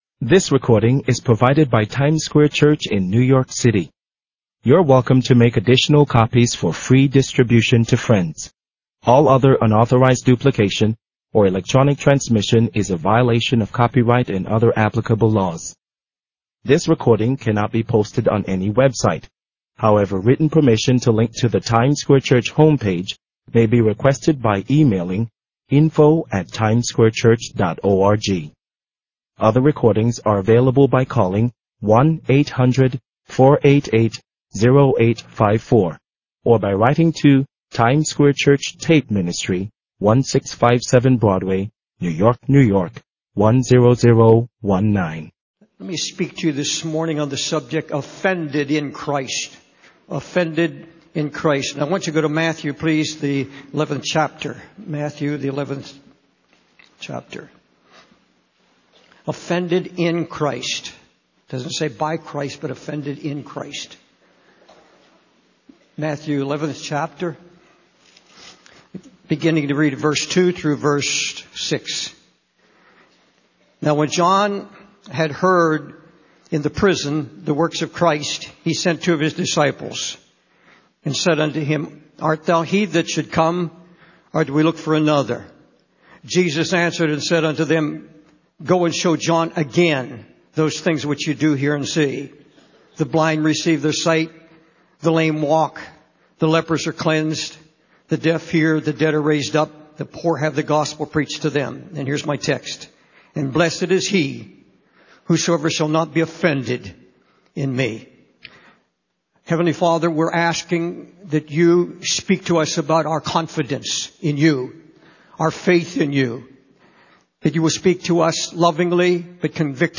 In this sermon, the preacher focuses on the book of James, specifically chapter one. He emphasizes the importance of finding joy in the midst of various temptations and trials, as these experiences help to strengthen our faith and develop patience.